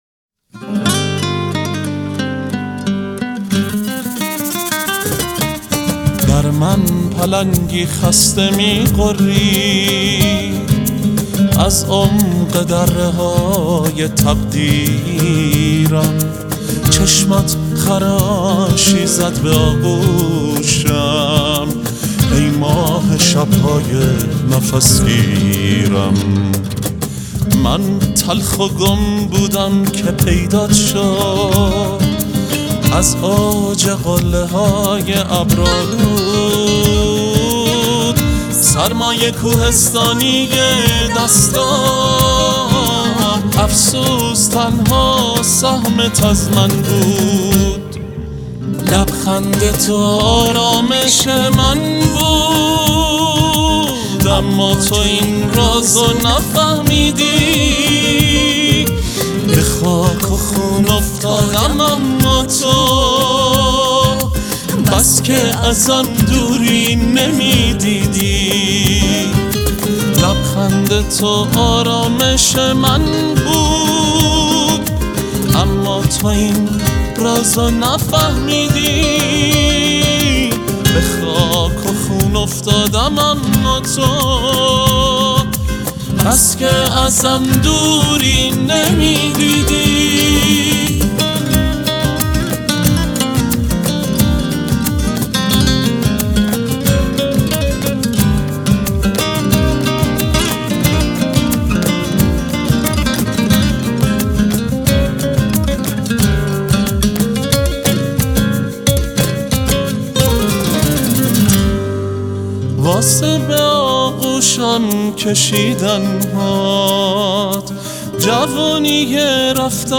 سبک آهنگ پاپ عاشقانه